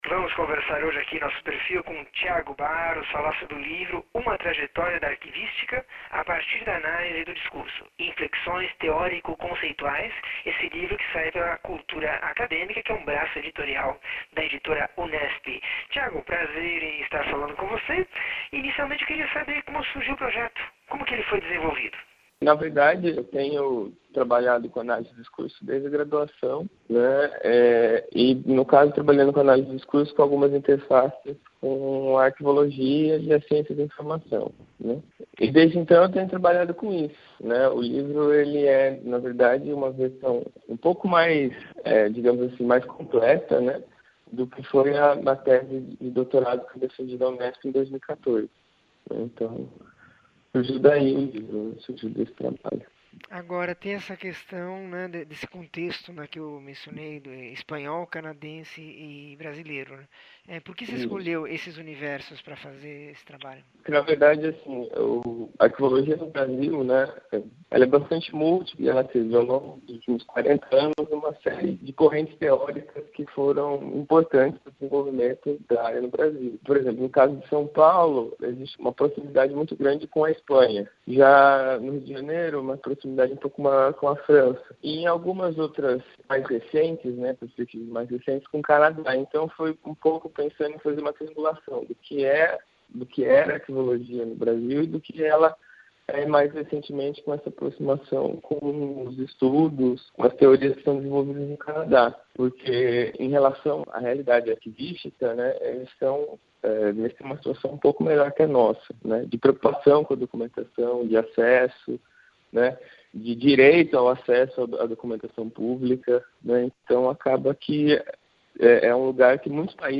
entrevista 2584